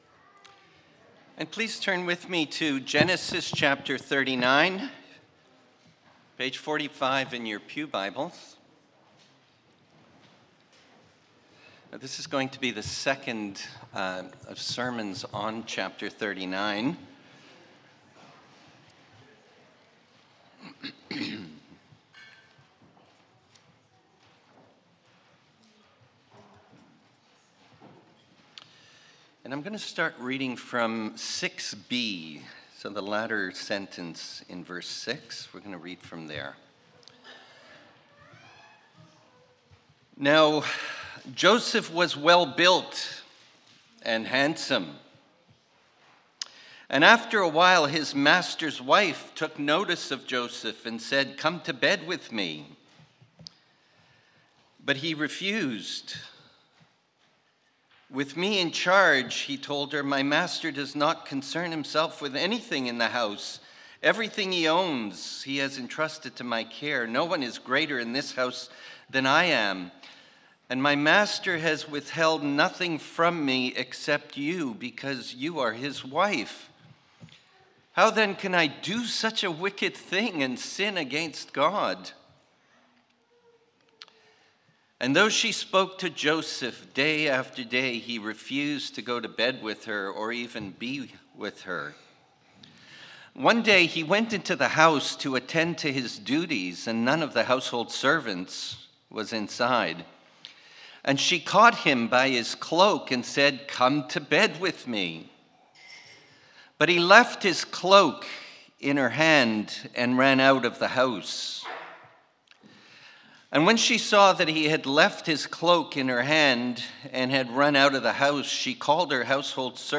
Sermons | Mountainview Christian Fellowship